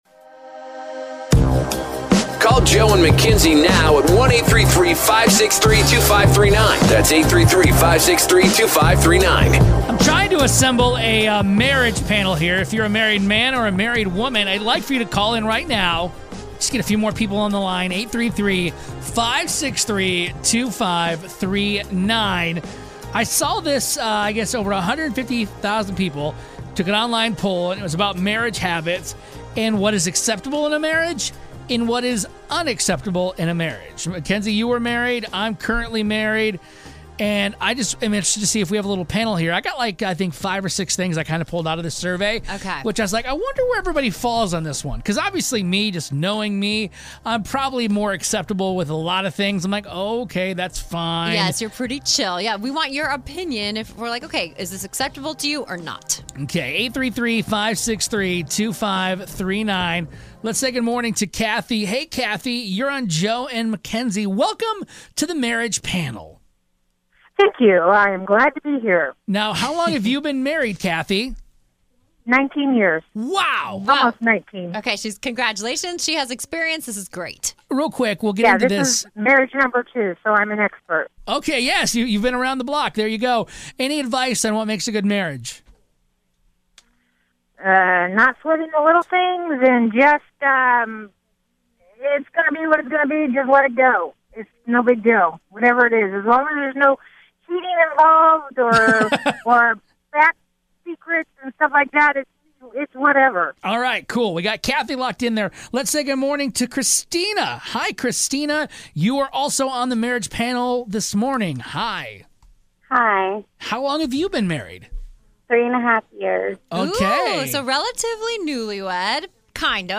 calling up married people to have a marriage panel to see if these things are okay or a definite NO.